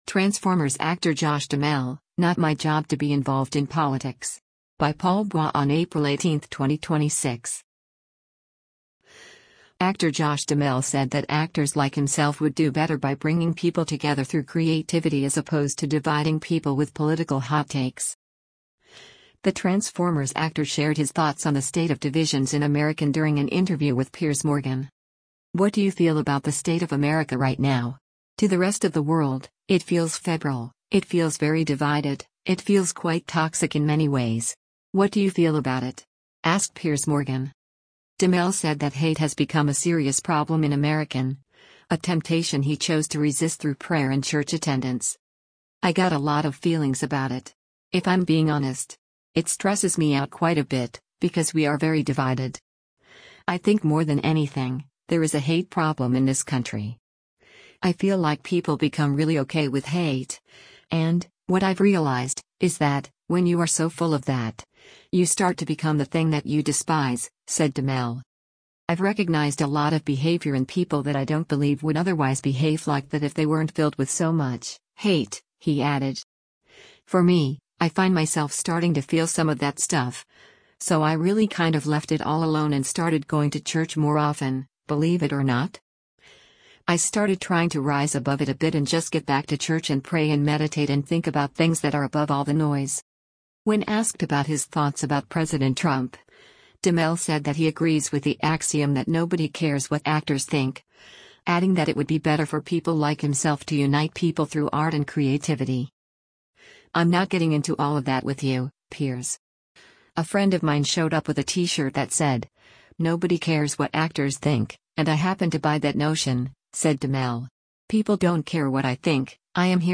The Transformers actor shared his thoughts on the state of divisions in American during an interview with Piers Morgan.